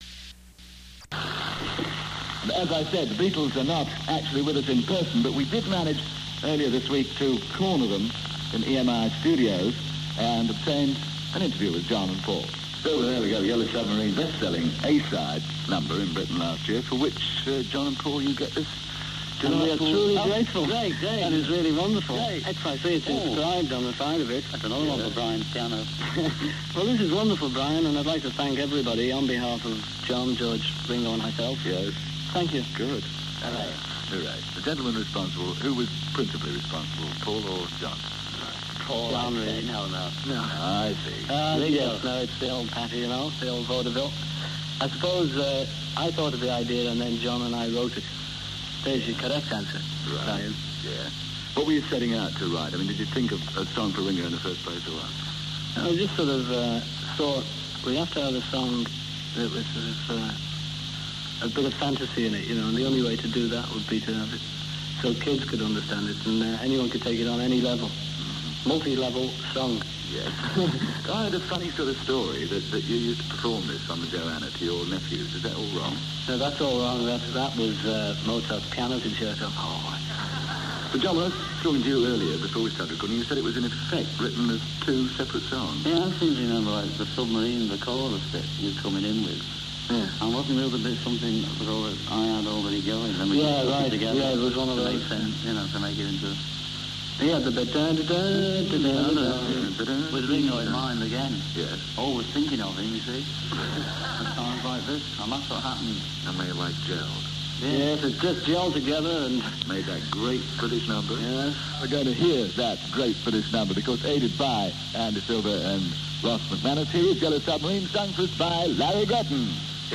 The BBC's Brian Matthew interviewed
These interviews were recorded on reel E63372.
Studio Two 7.00pm-3.00am